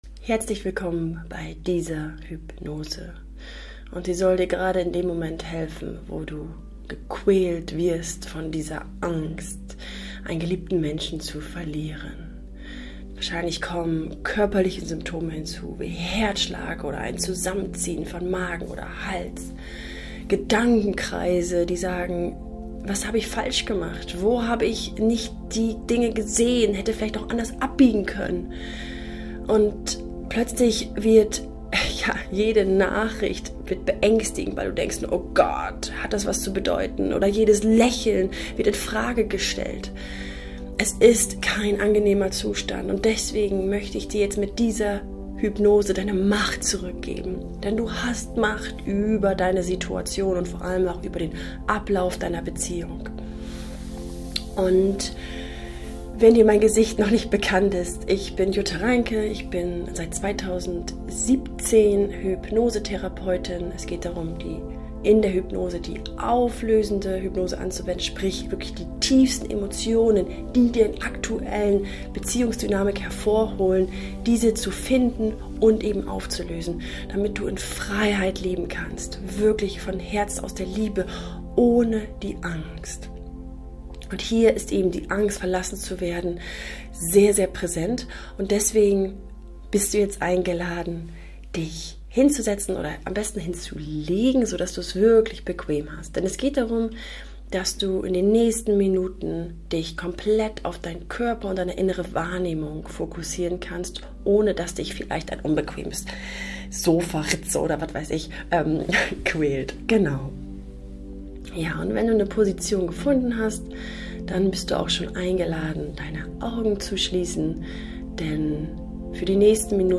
Hypnose zum Download Befreie Dich von Verlustangst & Eifersucht!
(Hypnose startet ab 1:30 Minute) Die Hypnose gibt es auch als Video: Sie sehen gerade einen Platzhalterinhalt von YouTube .